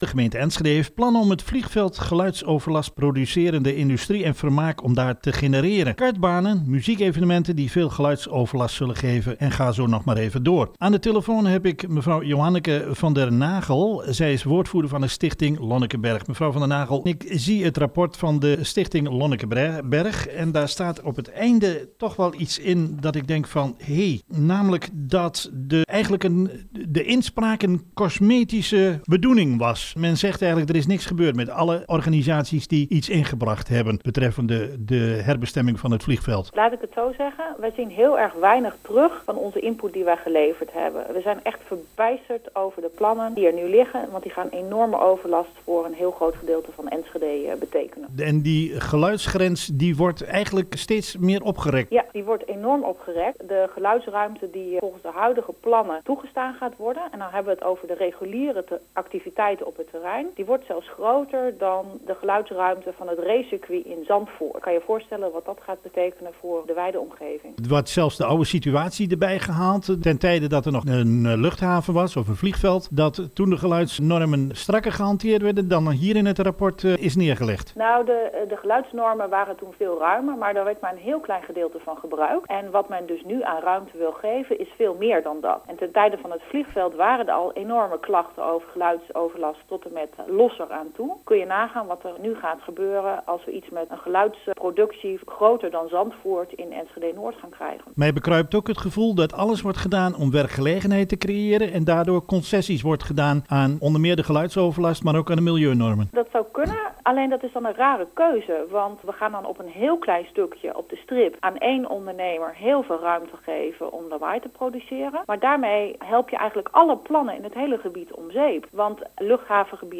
Interview met TVEnschede programma “Luchtig lunchen” over consequenties voor Enschede-Noord: